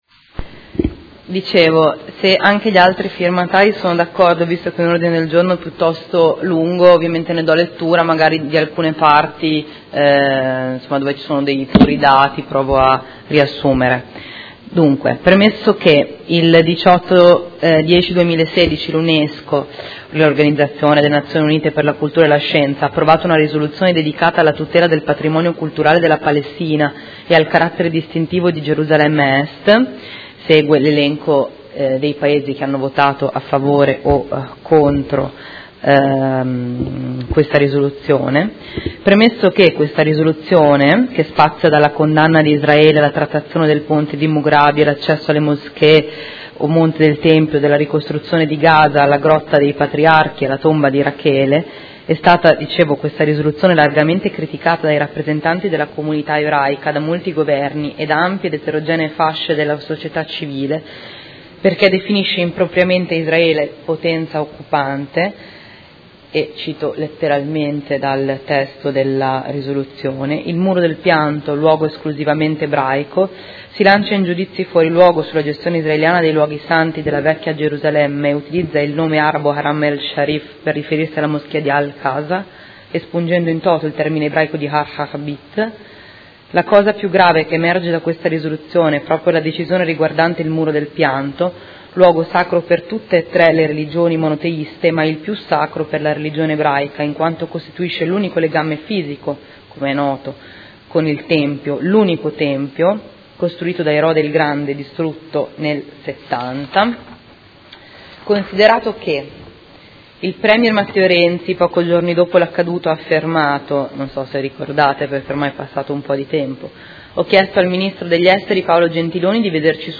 Federica Di Padova — Sito Audio Consiglio Comunale